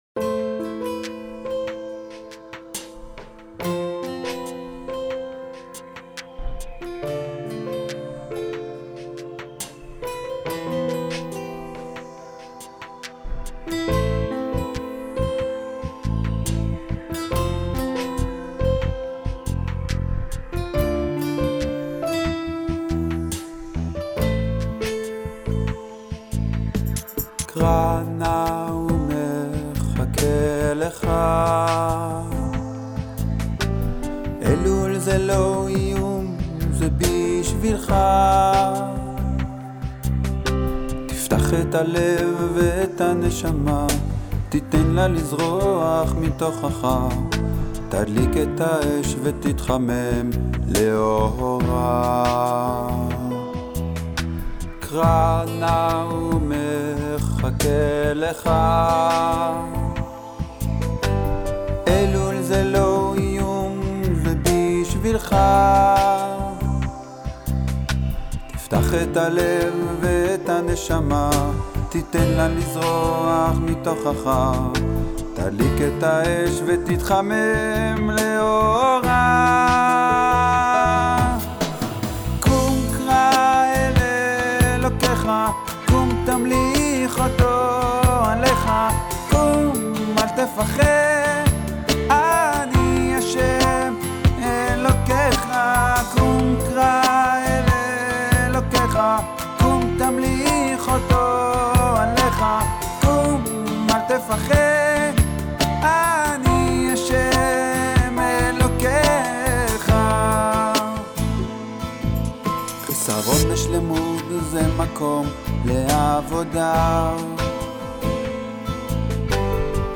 הלחן חביב, כנראה שיש לו פוטנציאל יותר ממה שהוא נשמע כרגע, אבל לא...
[כן, אני יודע, קולות שניים...] ברור לי שהוא לא ברמה מסחרית, אבל זה ממילא לא הכיוון שלי.